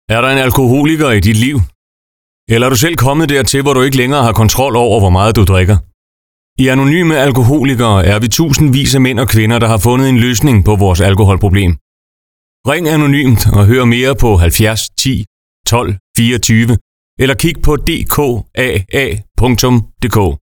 Informationsudvalget i Region Midtjylland har fået lavet nogle radiospots, der kort fortæller om AA og hvor du kan finde AA.
Der er allerede blevet brugt af lokalradiostationer.